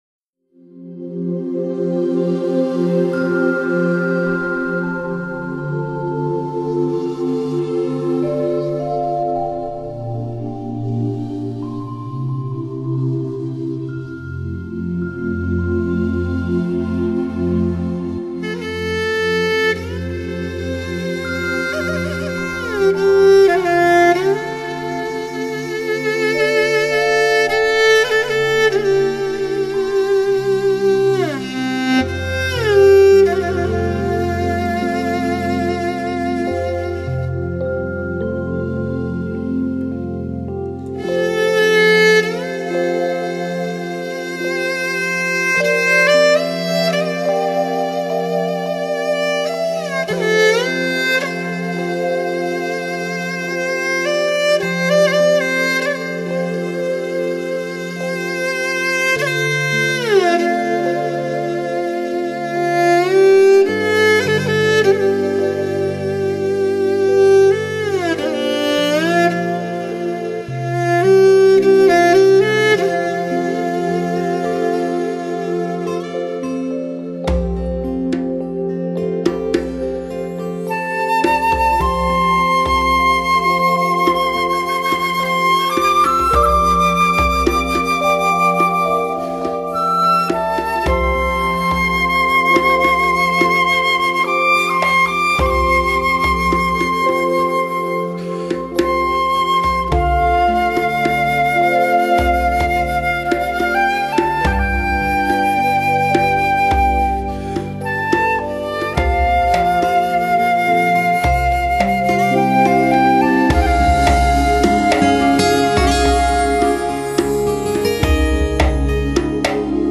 沉淀记忆 在宁静悠远中品味生活点滴
翱翔天宇 大气磅礴 抑扬间演绎天地的壮阔 顿挫间诉尽生命的情意